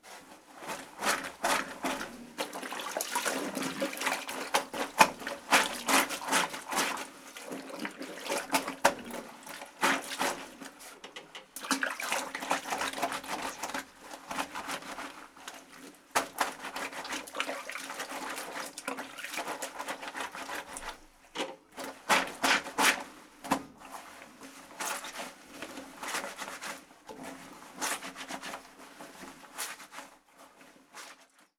Mujer lavando en un lavadero antiguo
lavadero
Sonidos: Acciones humanas
Sonidos: Rural
Sonidos: Hogar